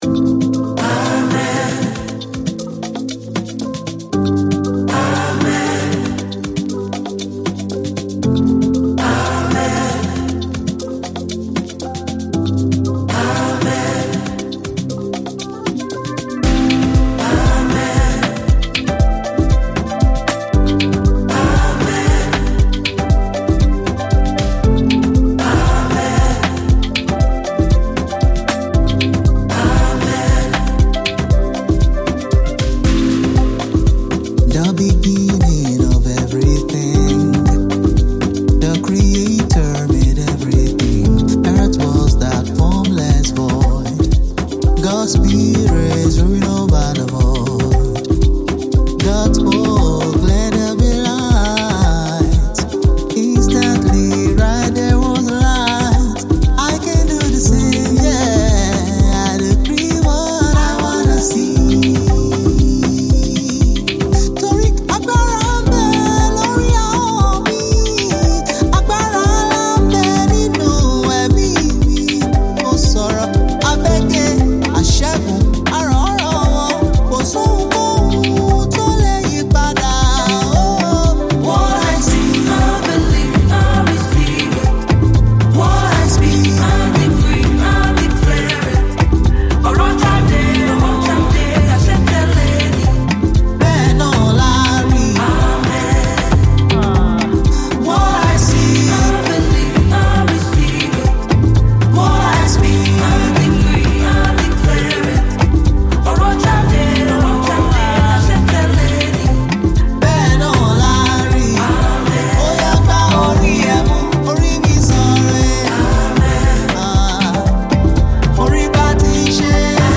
In this masterpiece amapiano sound